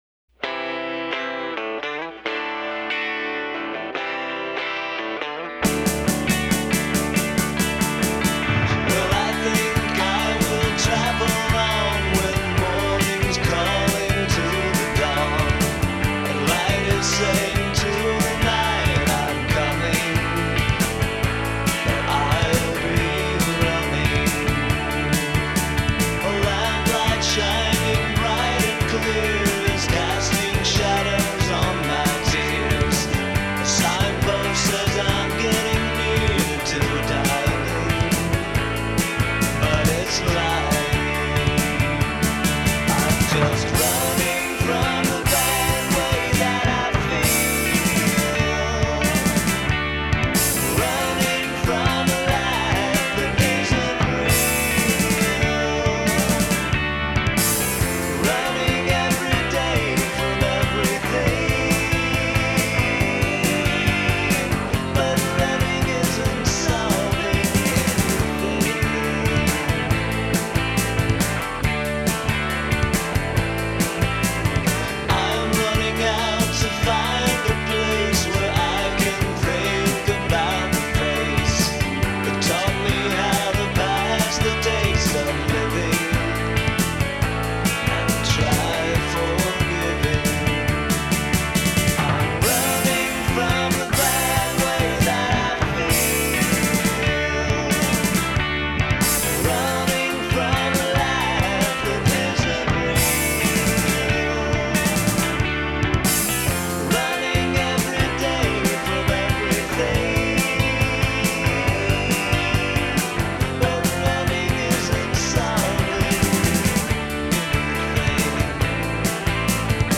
ringing Brydsian jangle